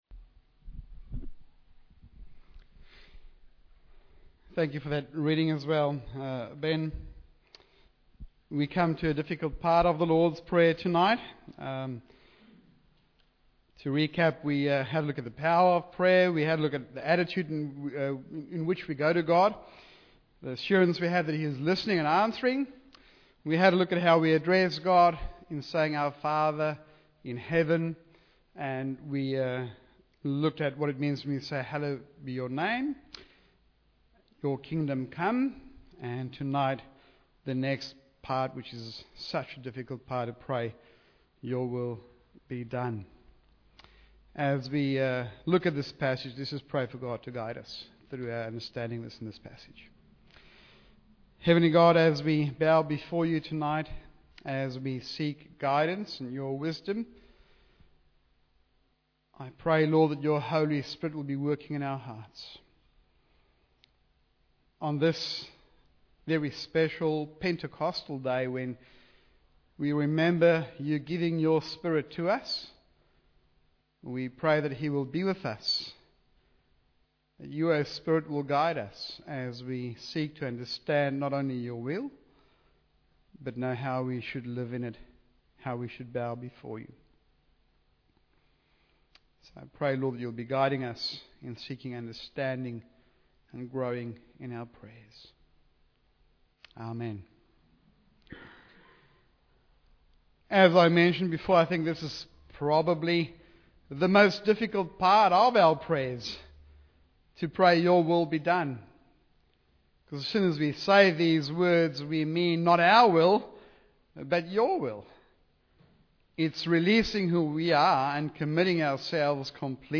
Your Will Be Done – Macquarie Chapel
Preacher